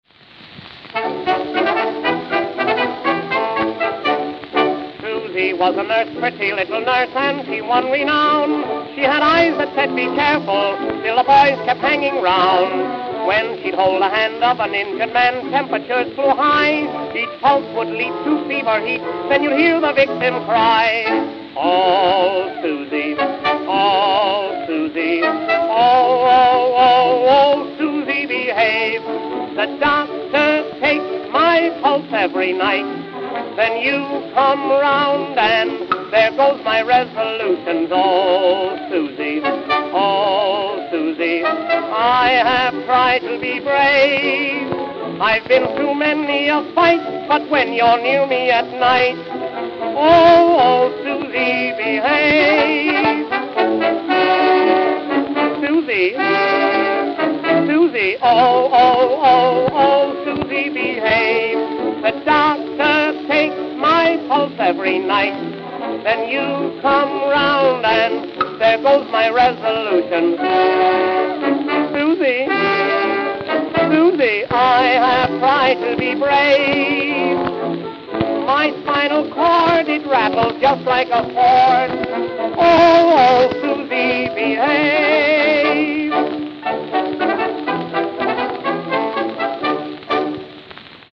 Tenor Solo